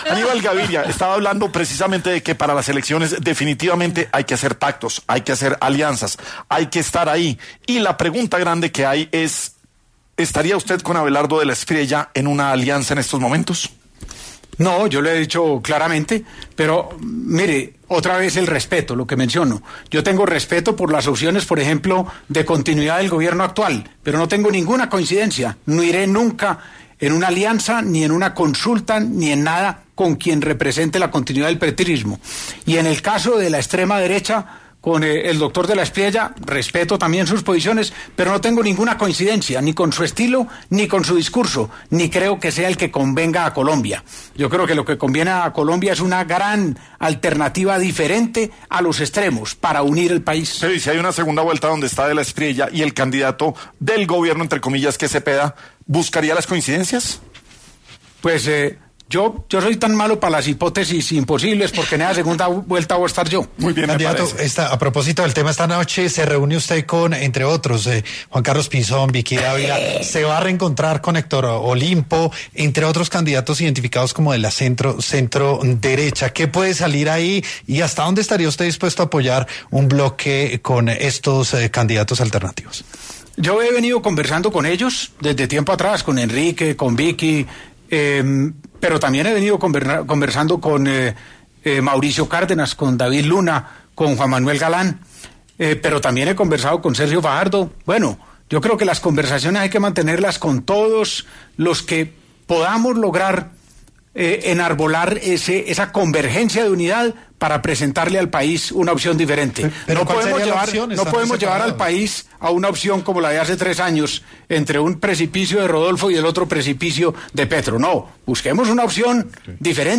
El aspirante a la presidencia y exgobernador de Antioquia, habló en ‘La Luciérnaga’ sobre posibles alianzas dentro de su estrategia electoral.